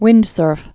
(wĭndsûrf)